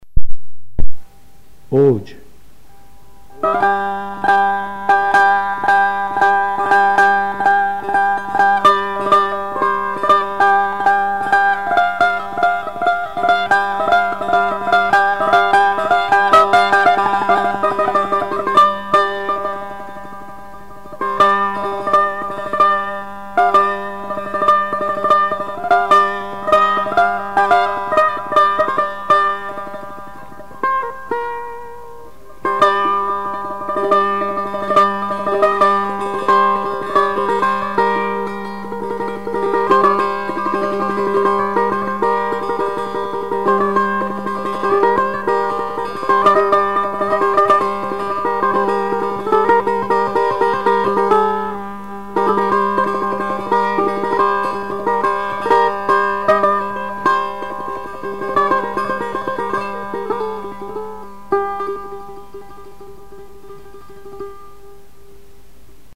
آواز دشتی ردیف میرزا عبدالله سه تار
اوج، آواز دشتی